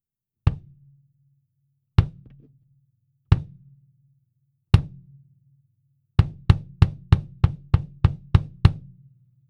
アタック部分がしっかり強調されている印象です。
もちもちサウンドですね！
EQ等は一切していません。
バスドラム　IN
112ドラムキックいん.wav